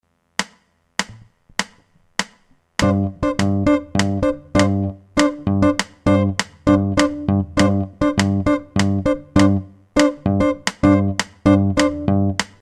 Un buon esercizio di indipendenza e consapevolezza ritmica può essere quello di suonare questi pattern ritmici, per iniziare, sulle note più acute (magari con un bicordo), mentre simultaneamente con una nota bassa scandiamo di volta in volta le note in battere oppure in levare [Es.7 ] [